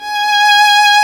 Index of /90_sSampleCDs/Roland L-CD702/VOL-1/STR_Violin 2&3vb/STR_Vln3 % marc
STR VLN3 G#4.wav